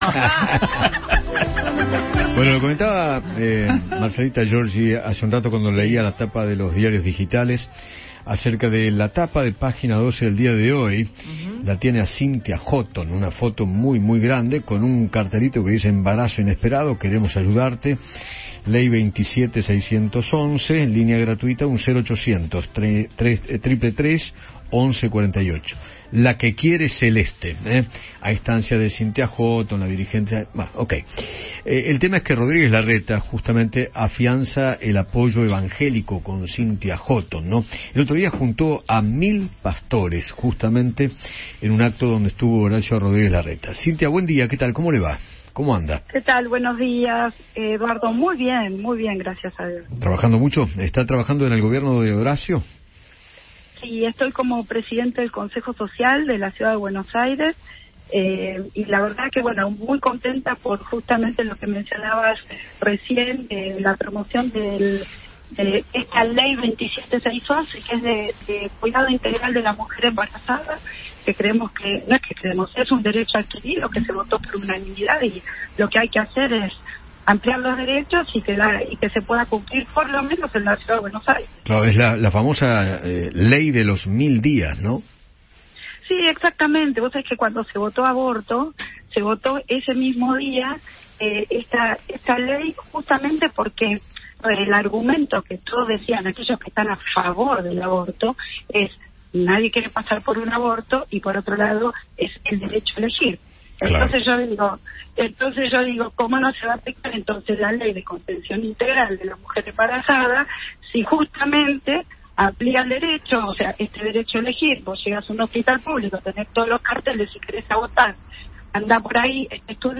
Cynthia Hotton, presidente del Consejo Social de la Ciudad de Buenos Aires, habló con Eduardo Feinmann sobre el apoyo evangélico a Rodríguez Larreta y se refirió a la nueva cartelería en los hospitales públicos porteños “0800 VIDA”.